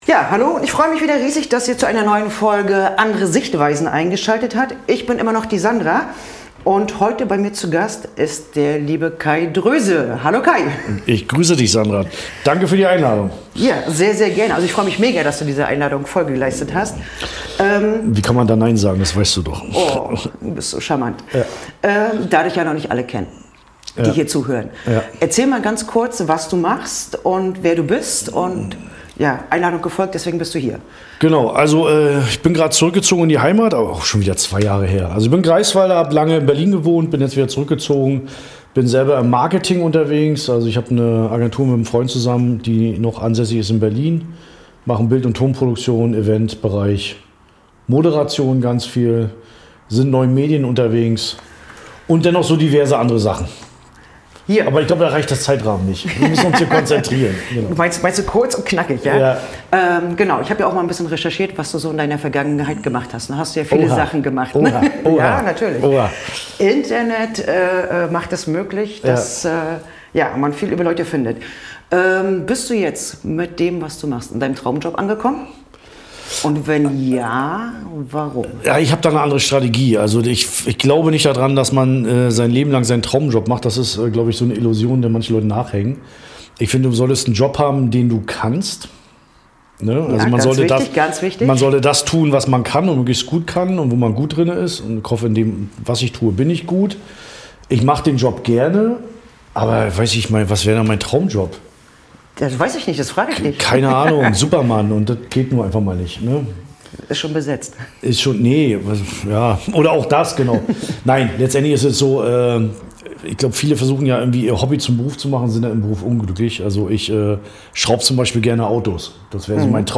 Kai Dröse, Bürgermeister und Unternehmer aus der Marketing-Branche, und ich unterhalten uns über die Thematik Marketing, erreichen von Zielsetzungen, Prioritäten-Verteilung.